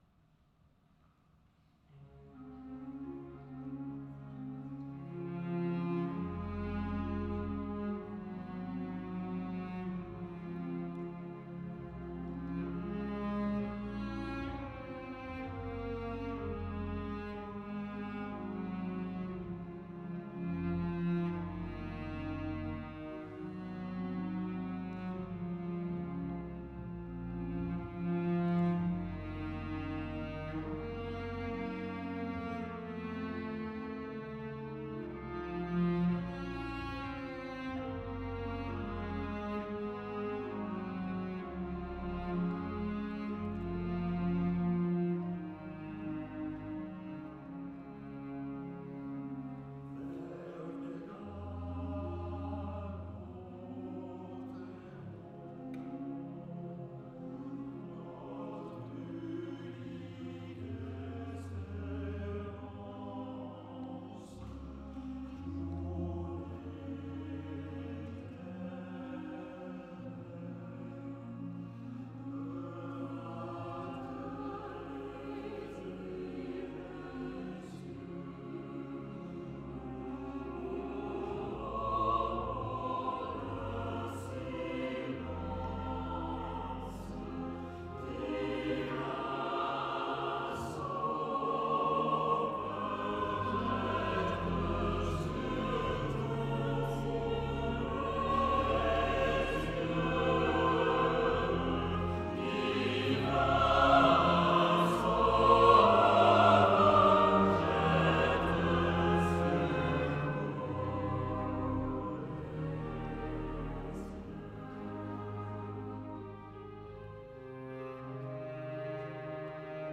L’œuvre maîtresse du concert était accompagnée par quelques-unes des plus belles pages pour orgue et chœur de César Franck et Gabriel Fauré, ainsi que par une pièce pour orgue seul de Camille Saint-Saëns.